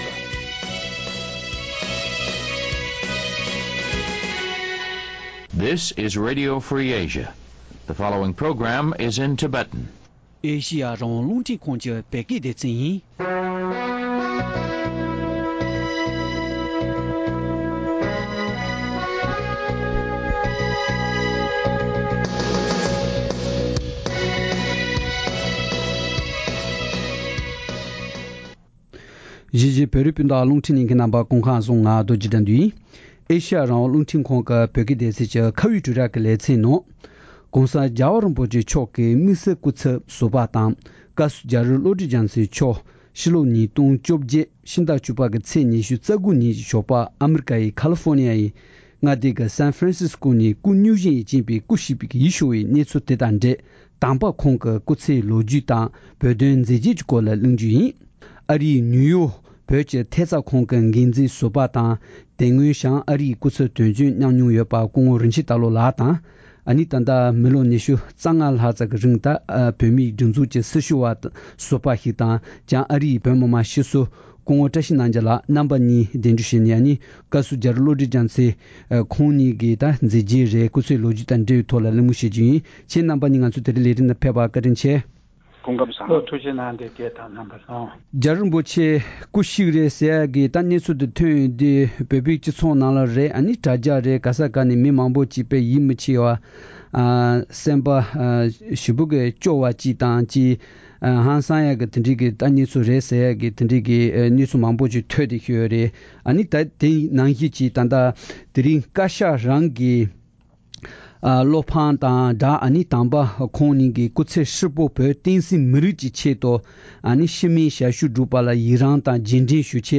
བཀའ་ཟུར་རྒྱ་རི་བློ་གྲོས་རྒྱལ་མཚན་རིན་པོ་ཆེ་མཆོག་གི་མཛད་རྗེས་ཐད་གླེང་མོལ།